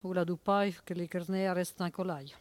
Langue Maraîchin
Patois - archive
Catégorie Locution